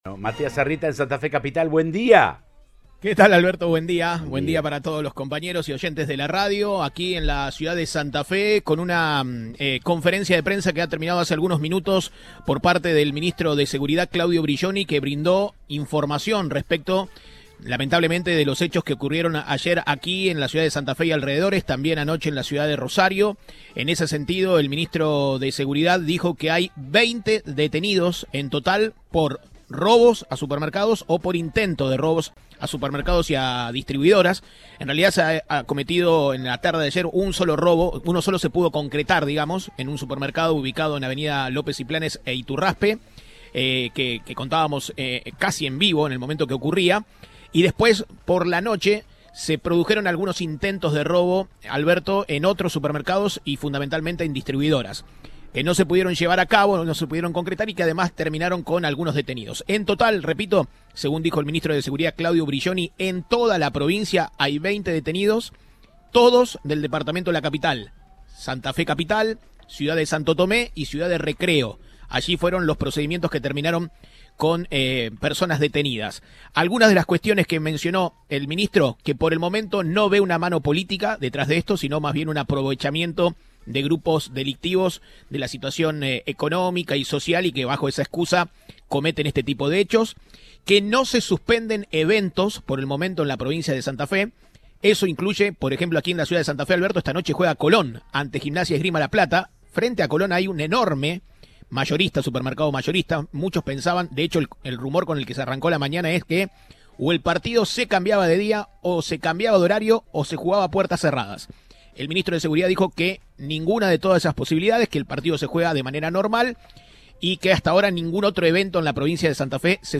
El ministro, en contacto con el móvil de Cadena 3 Rosario en Santa Fe, en Siempre Juntos, explicó: “A Rosario viajé urgente porque se estaban dando intentos de ingresos a supermercados, repelidos inmediatamente por la policía”.